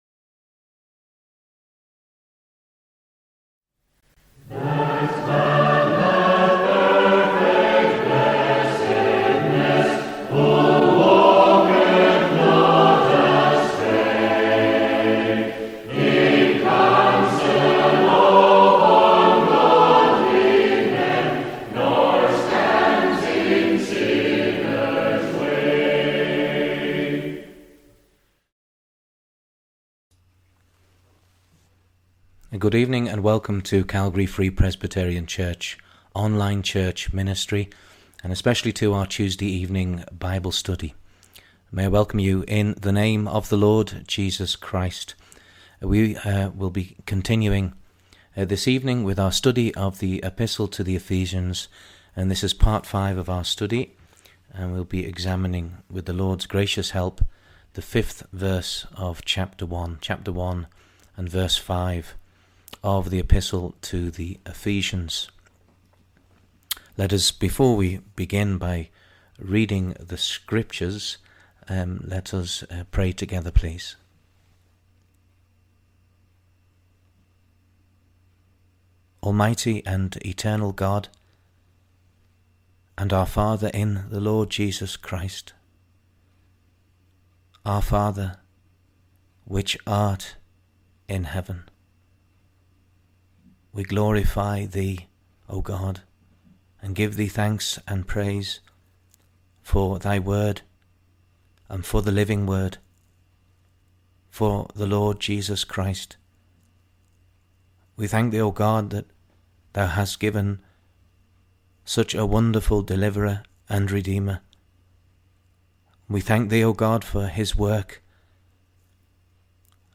Bible Study - Epistle to..